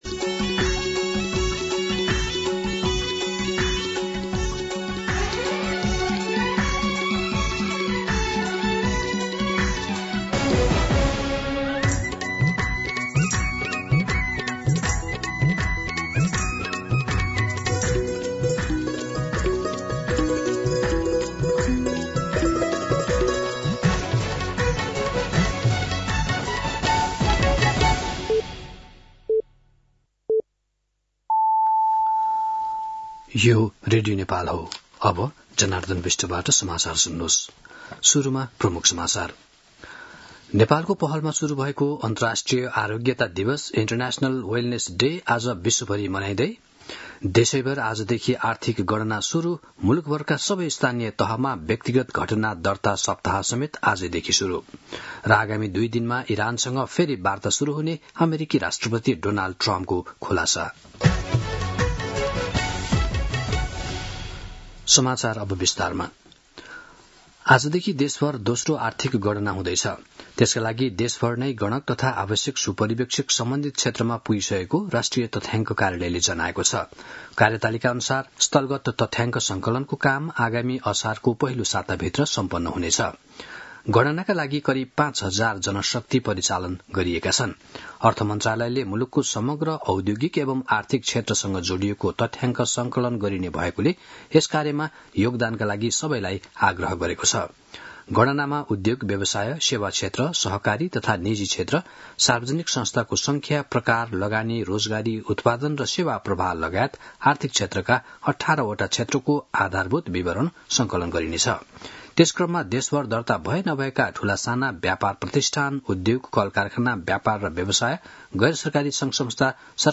दिउँसो ३ बजेको नेपाली समाचार : २ वैशाख , २०८३